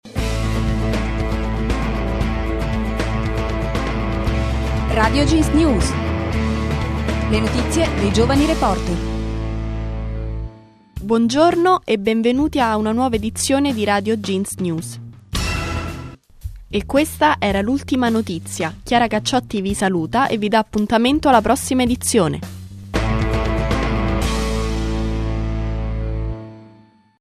Le notizie dei giovani reporter